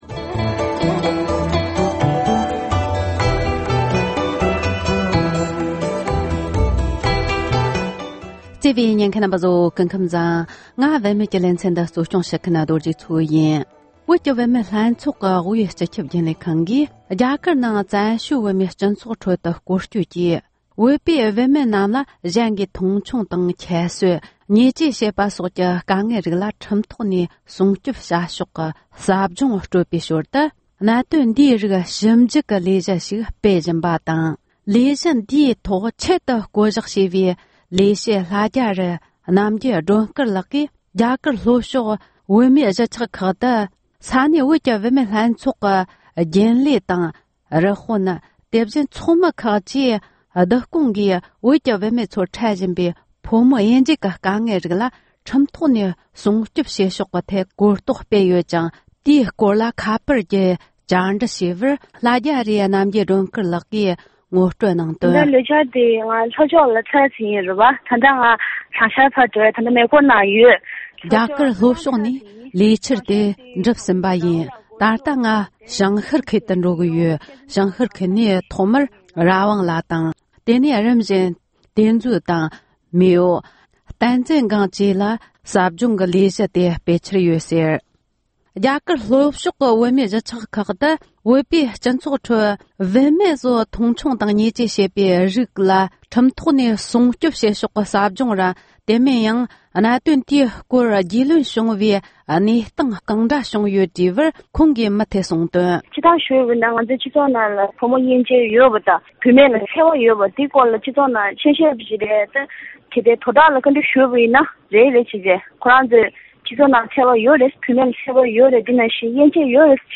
སྒྲ་ལྡན་གསར་འགྱུར།
༄༅༎དེ་རིང་གི་བུད་མེད་ཀྱི་ལེ་ཚན་འདིའི་ནང་དུ་དབུས་བོད་ཀྱི་བུད་མེད་ལྷན་ཚོགས་ཀྱིས་རྒྱ་གར་གྱི་བོད་མིའི་སྤྱི་ཚོགས་ཁྲོད་དུ་བུད་མེད་ཚོའི་རང་དབང་ཐོབ་ཐང་ཁག་ལ་ཁྲིམས་ཐོག་ནས་སྲུང་སྐྱོབ་བྱ་ཕྱོགས་ཐད་གོ་རྟོགས་སྤེལ་བའི་ཟབ་སྦྱོང་ལས་འགུལ་ཞིག་སྤེལ་བཞིན་པའི་སྐོར་ལ་འབྲེལ་ཡོད་མི་སྣར་བཅར་འདྲི་དང་གནས་ཚུལ་ཕྱོགས་སྒྲིག་བྱས་པར་ཉན་རོགས་གནོངས༎